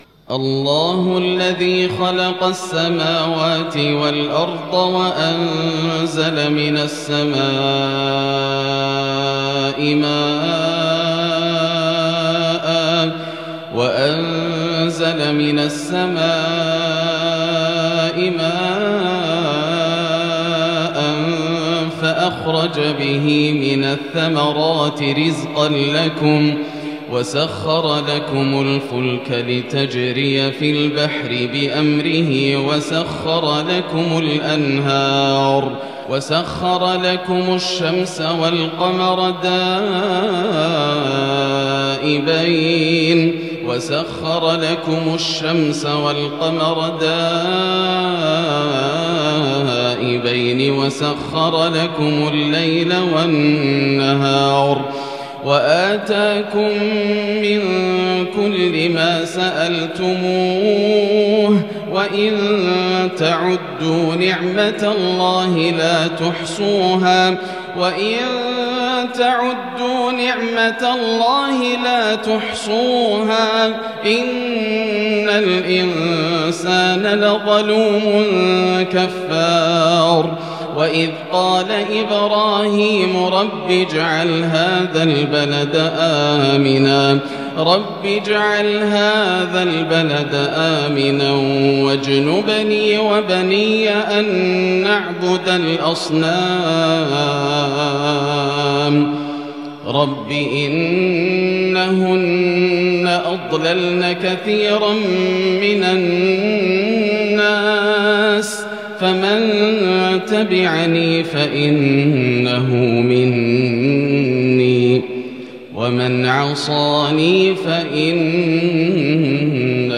مغرب السبت 6-4-1442هـ من سورة  إبراهيم Maghrib prayer from Surat Ibrahim 21/11/2020 > 1442 🕋 > الفروض - تلاوات الحرمين